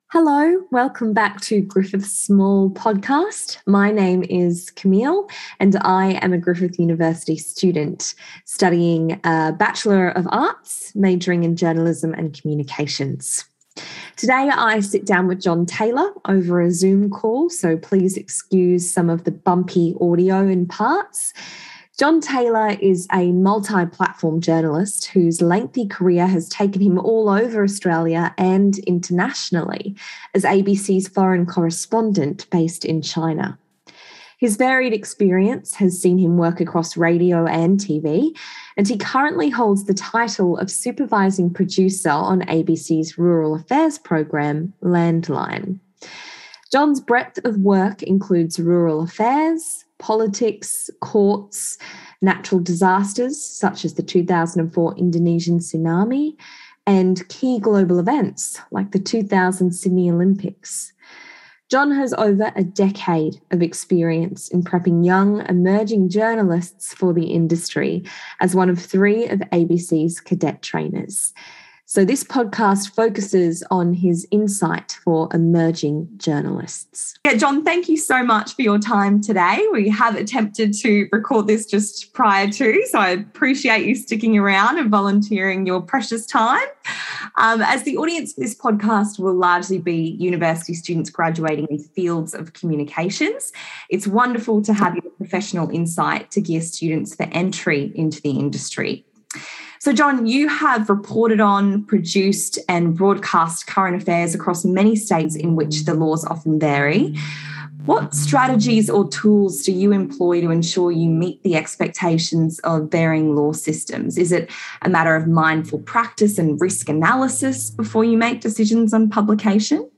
Welcome to Griffith SMALL (Social Media And Law Livestream) where we interview experts on a range of aspects of social media law.